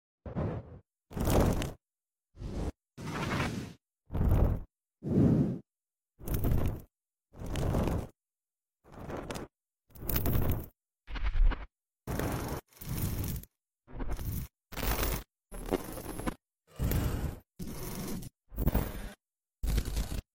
Sorry I added the music by mistake